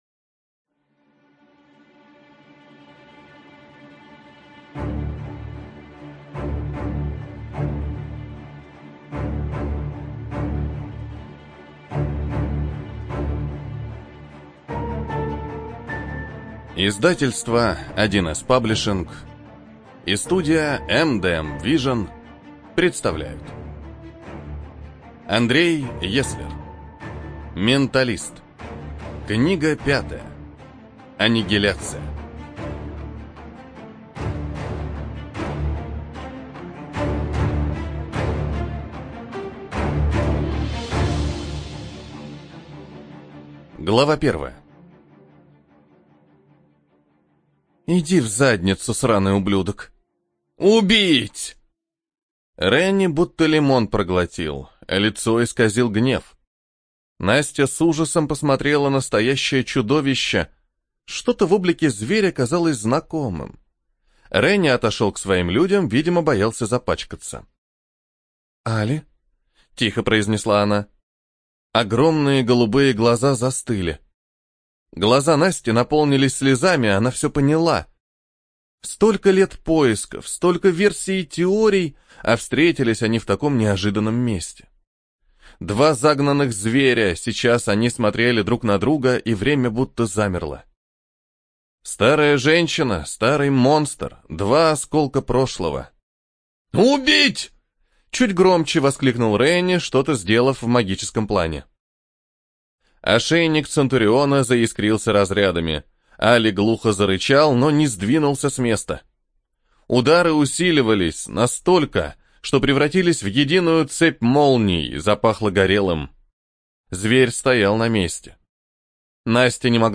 Студия звукозаписи1С-Паблишинг